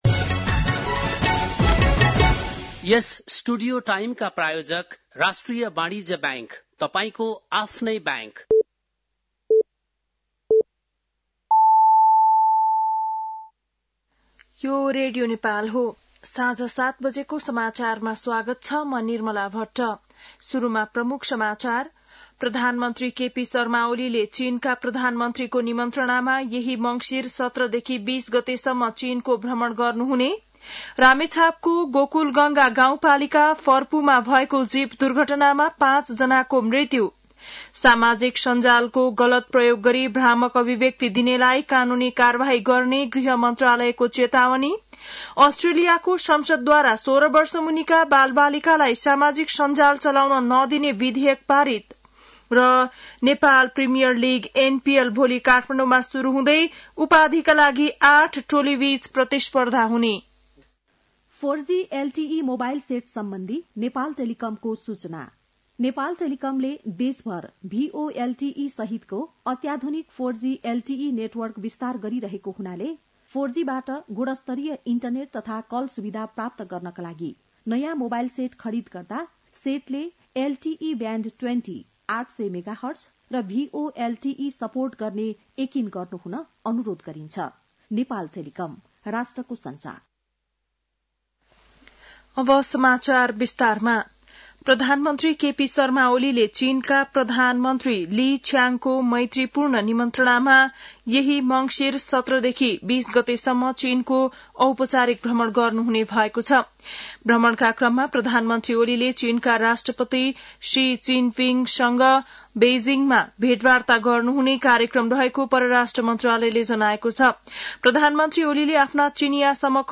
बेलुकी ७ बजेको नेपाली समाचार : १५ मंसिर , २०८१
7-pm-nepali-news-8-14.mp3